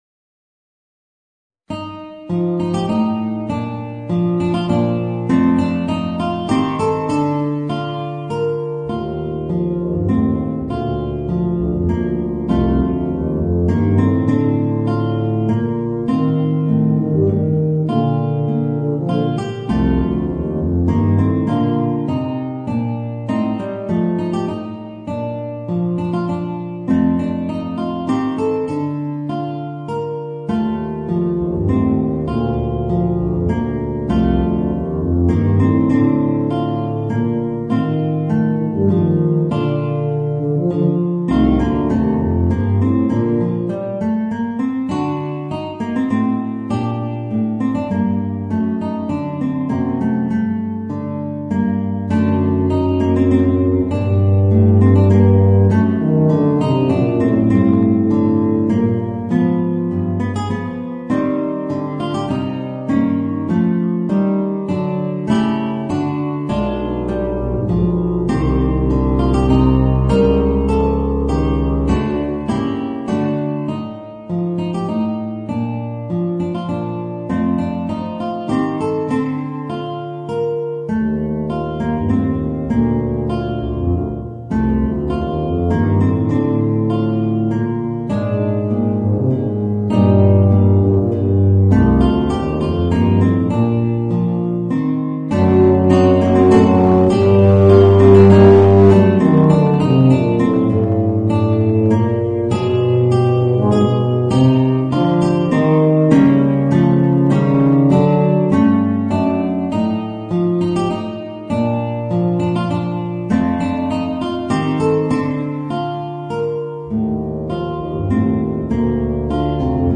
Voicing: Guitar and Tuba